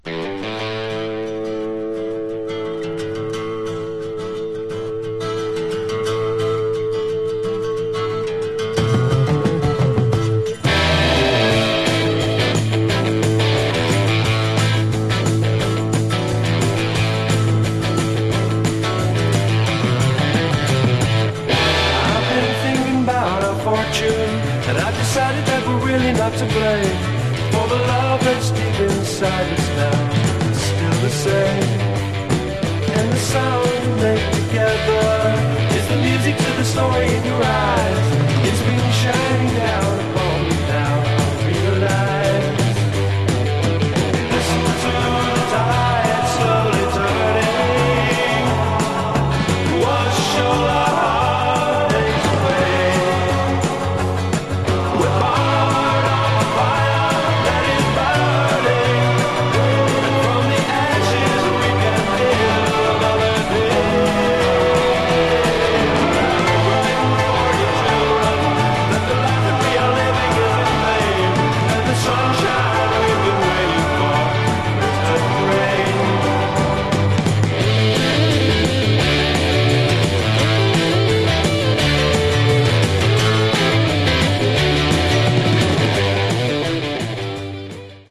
Genre: Hard Rock/Metal